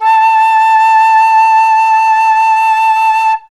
51c-flt21-A4.wav